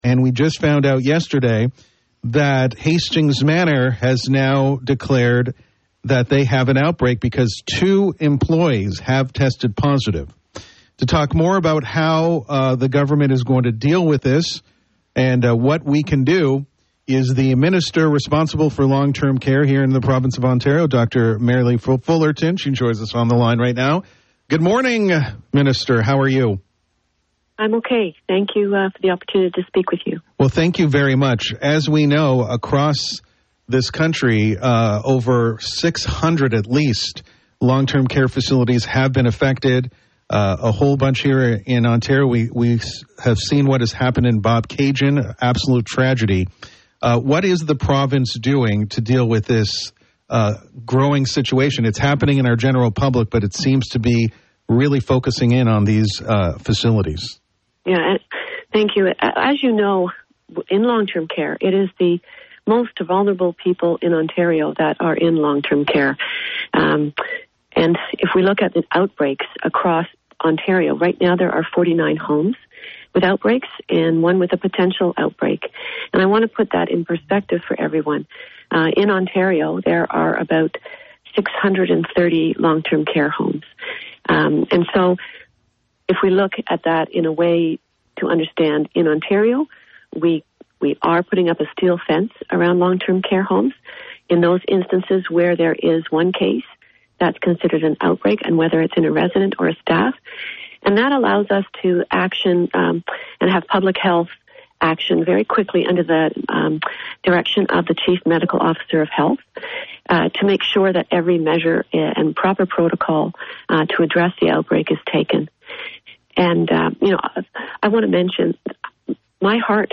Here is the full interview with Ontario Minister of Long Term Care Dr. Merrilee Fullerton: